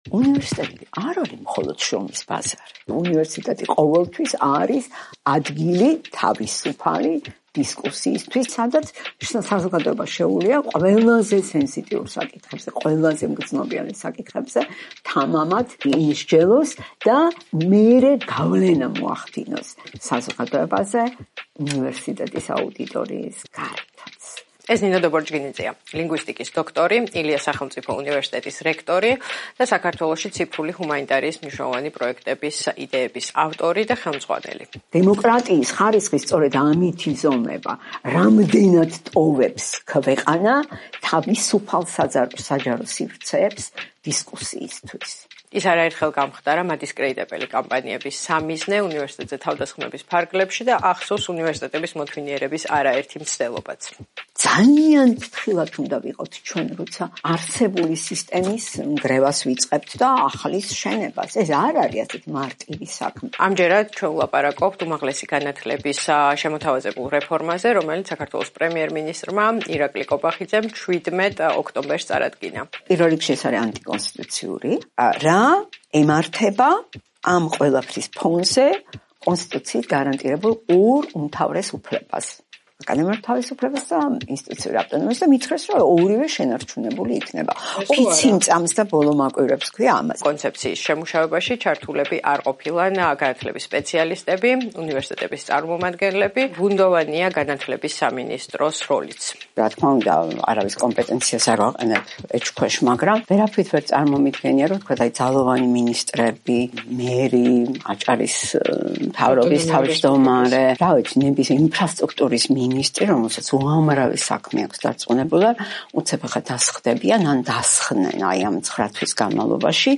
როგორ დაიცავენ უნივერსიტეტები თავს და აკადემიურ თავისუფლებას - ინტერვიუ